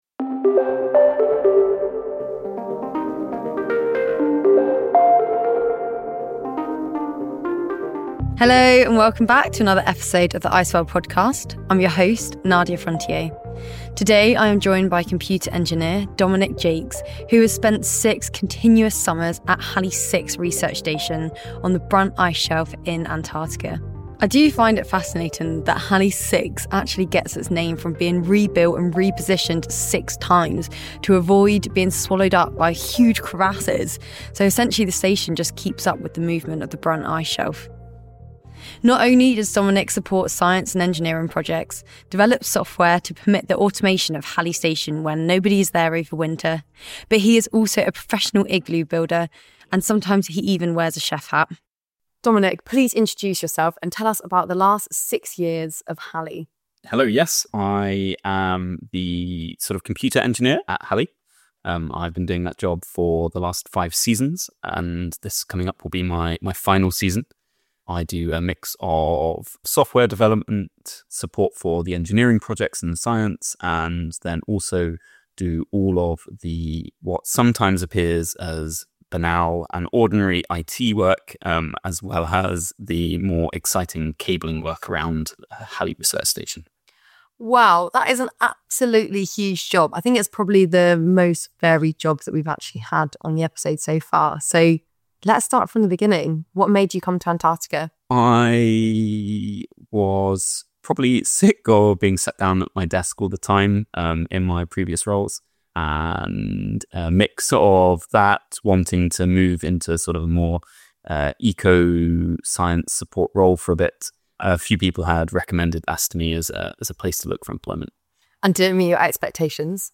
From polar scientists to plumbers, ICEWORLD is a series of interviews with ordinary people who are doing extraordinary jobs in Antarctica. The team talk climate science, extreme living, expeditions and becoming a community.